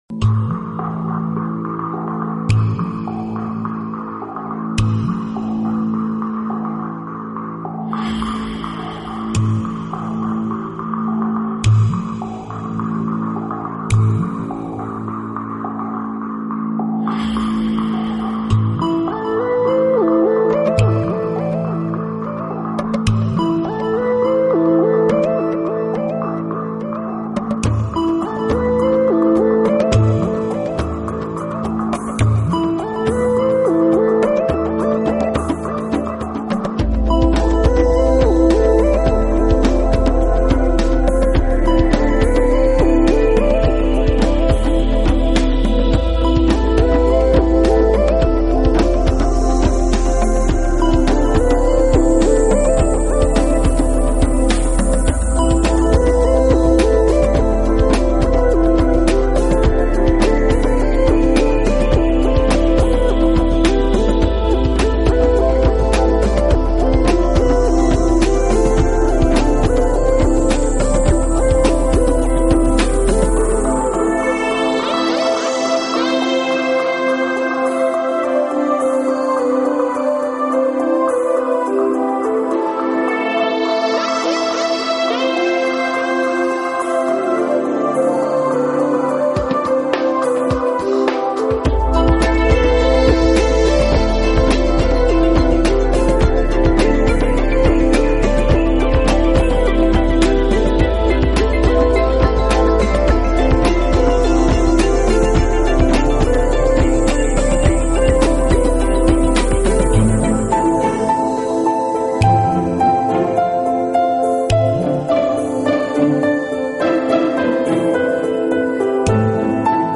【纯音乐】
音乐时而宏伟壮丽，时而深沉
得非常有穿透力，精巧钟鸣，耳语般吟诵和格里高利圣咏，伴随着飞翔的弦乐，